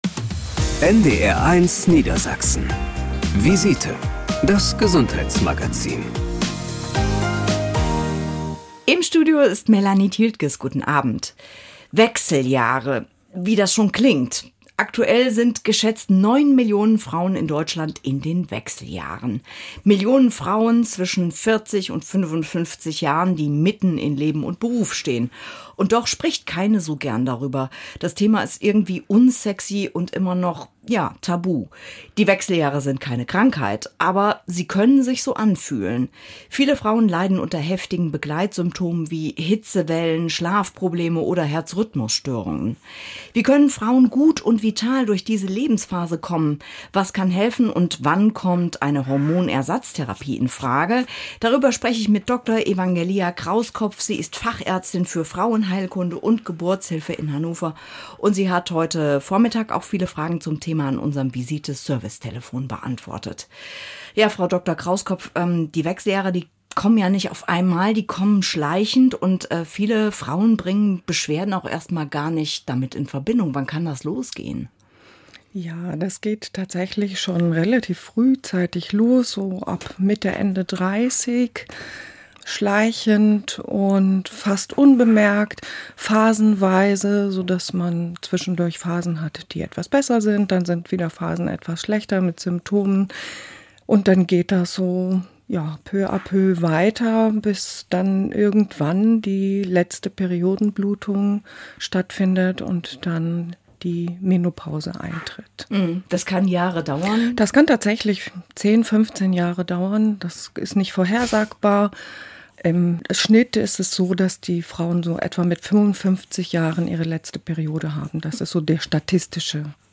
Fachärzte im Studio beantworten Hörerfragen am Telefon und geben gute Ratschläge zur Erhaltung der Gesundheit.